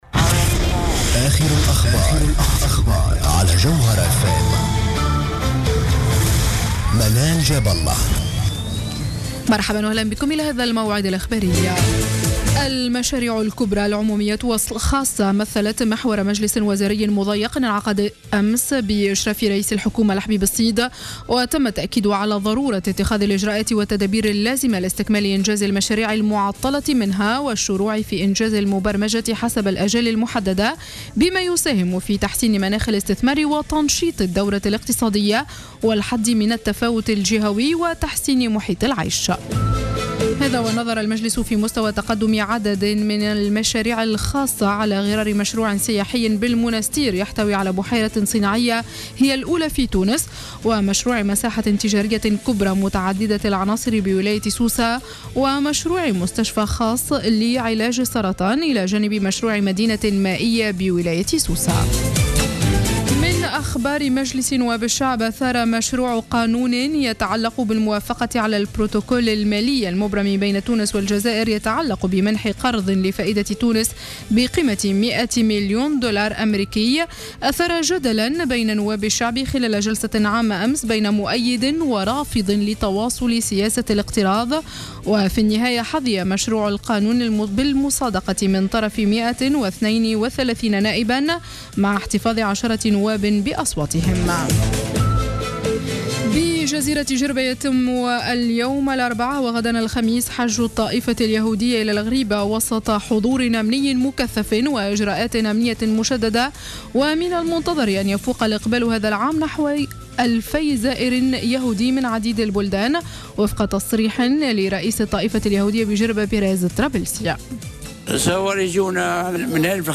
نشرة أخبار منتصف الليل ليوم الإربعاء 6 ماي 2015